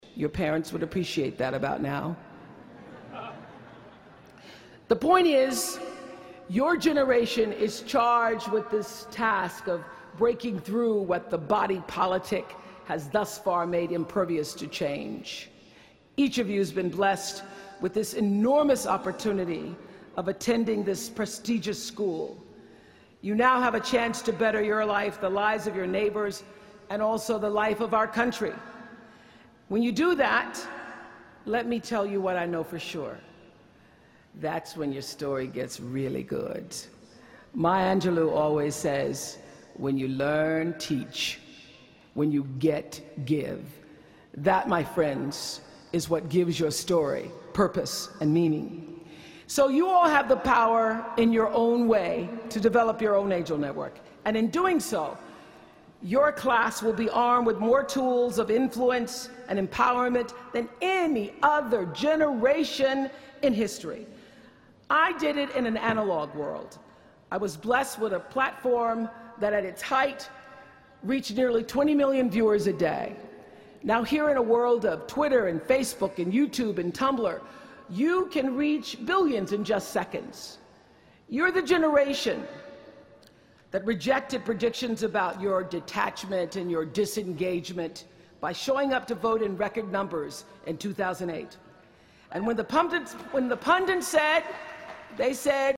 公众人物毕业演讲第363期:奥普拉2013在哈佛大学(12) 听力文件下载—在线英语听力室